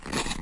削铅笔机 " 削铅笔机 3
Tag: 每天的生活中 办公室 学校 铅笔